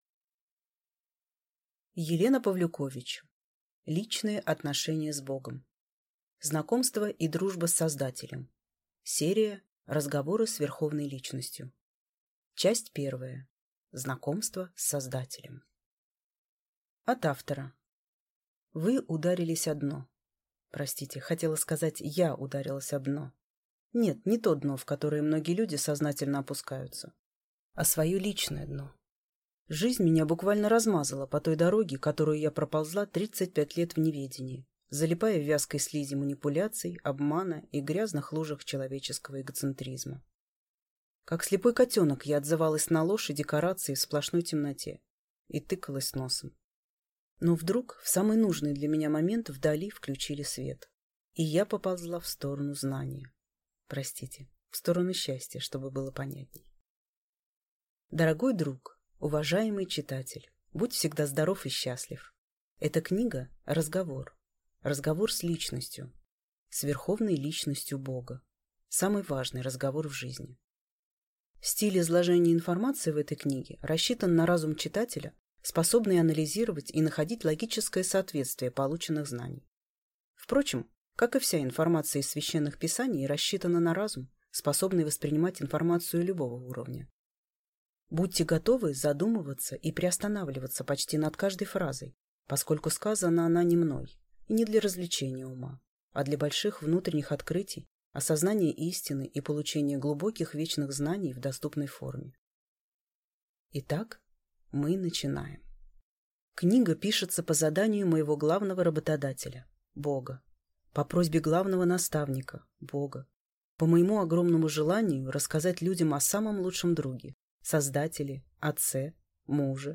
Аудиокнига Личные отношения с Богом. Знакомство и Дружба с Создателем.